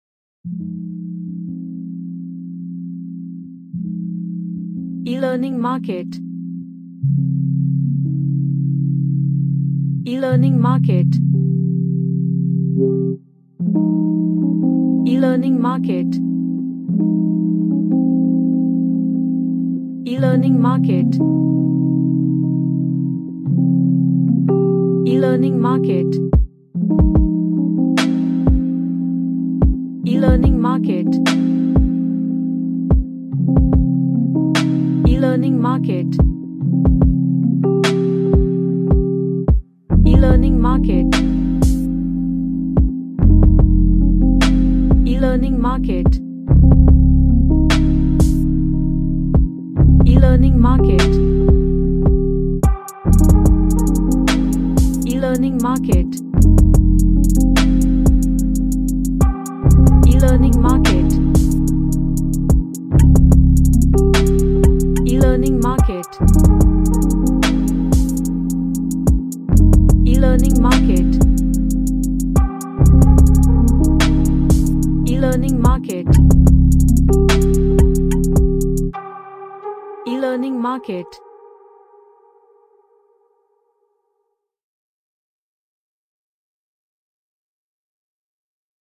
An ambient R&B track
Ambient